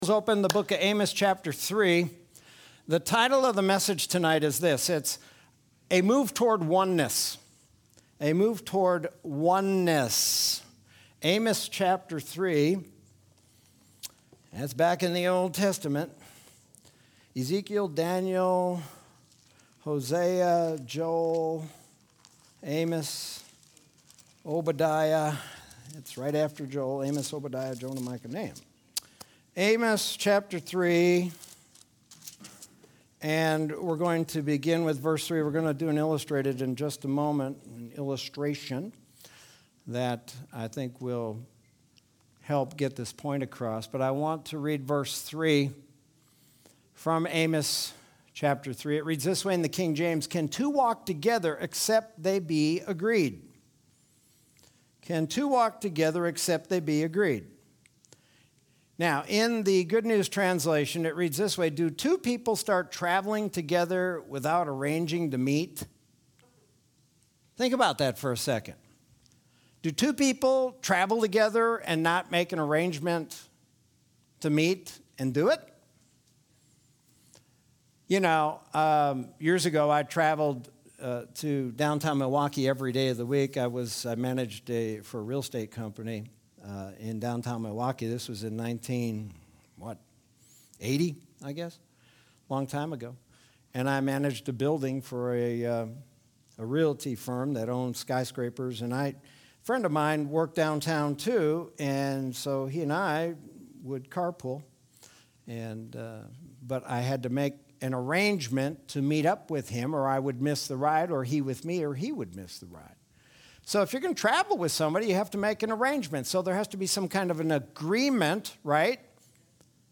Sermon from Wednesday, June 30th, 2021.